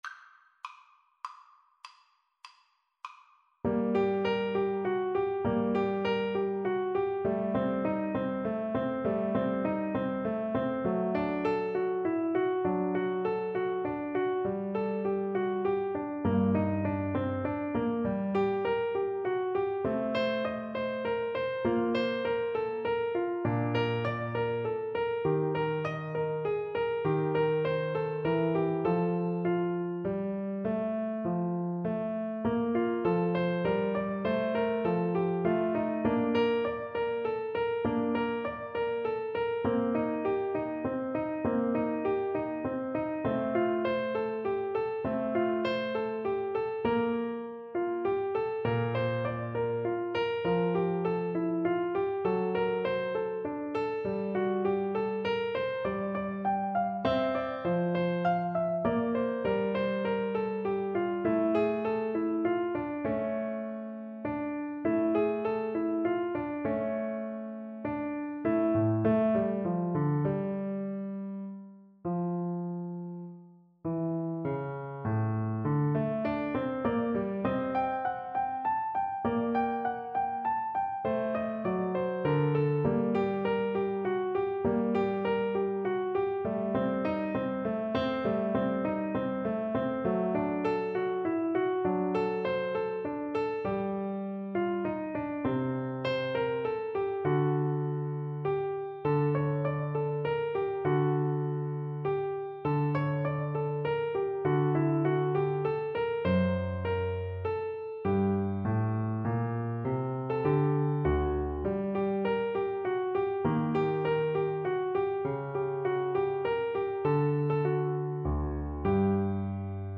6/8 (View more 6/8 Music)
Siciliano =100
Classical (View more Classical Viola Music)